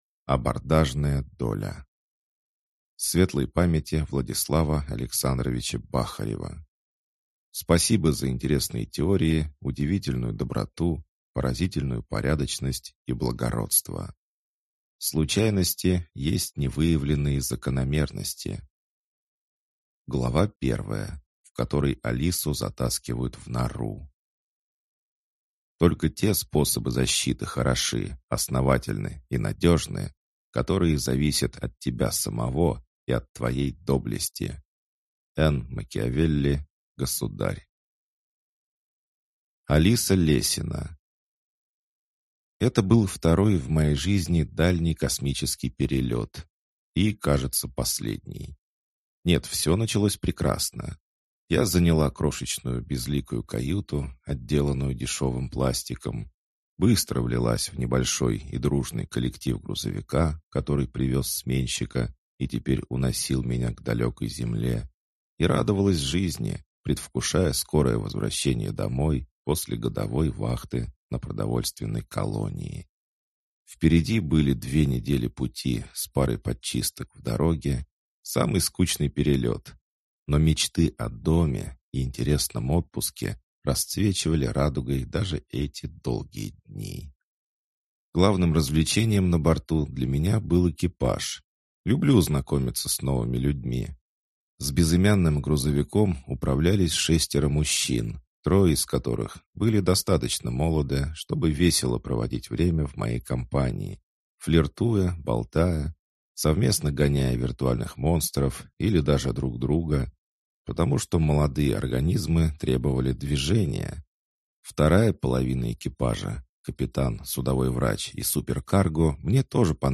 Аудиокнига Абордажная доля | Библиотека аудиокниг
Прослушать и бесплатно скачать фрагмент аудиокниги